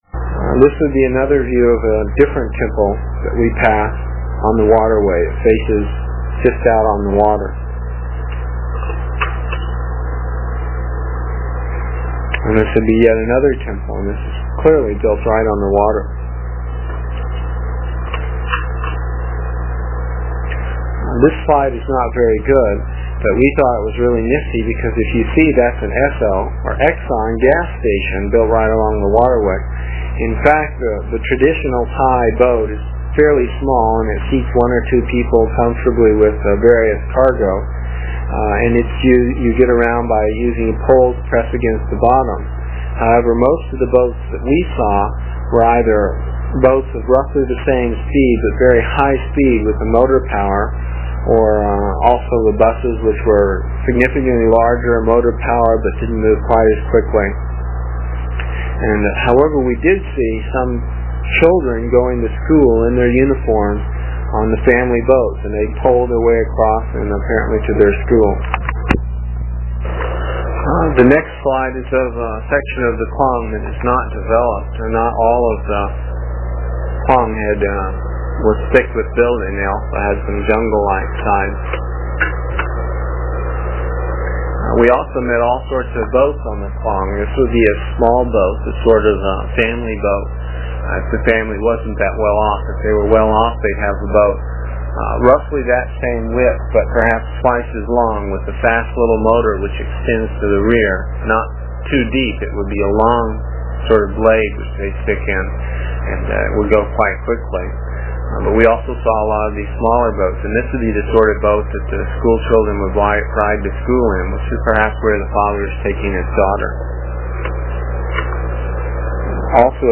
voice description of each slide that you can listen to while looking at the slides. It is from the cassette tapes we made almost thirty years ago. I was pretty long winded (no rehearsals or editting and tapes were cheap) and the section for this page is about ten minutes and will take about four minutes to download with a dial up connection.